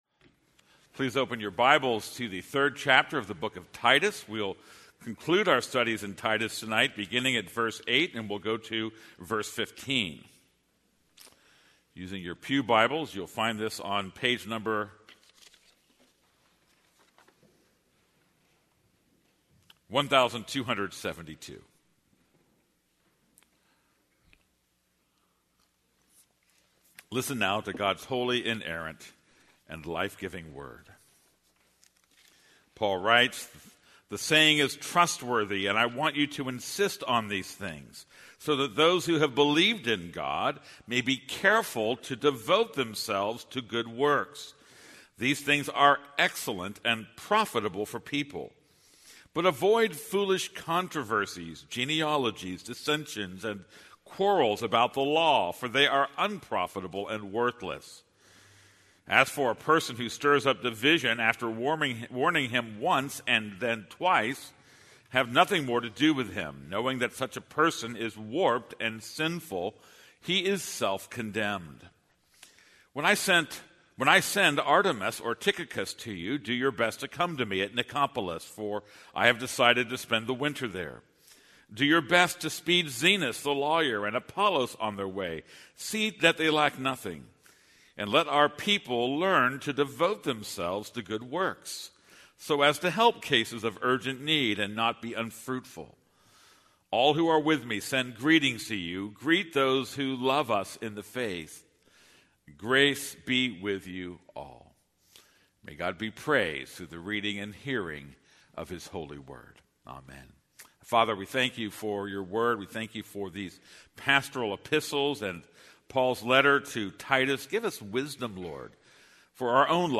This is a sermon on Titus 3:8-15.